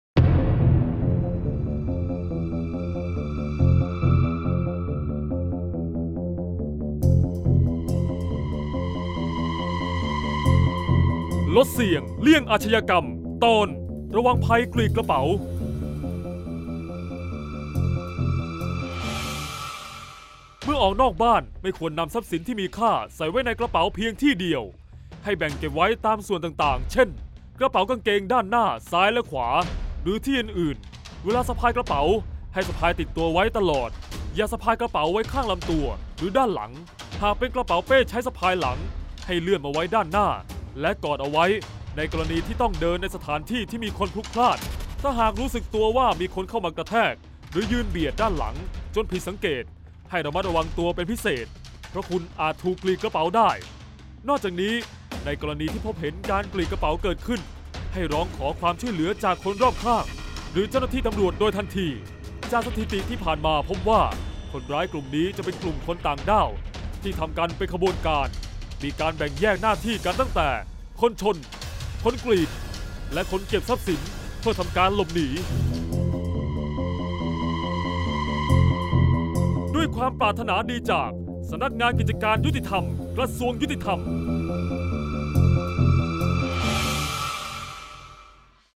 เสียงบรรยาย ลดเสี่ยงเลี่ยงอาชญากรรม 45-ระวังภัยกรีดกระเป๋า